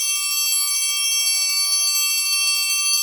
Index of /90_sSampleCDs/Roland LCDP03 Orchestral Perc/PRC_Orch Toys/PRC_Orch Triangl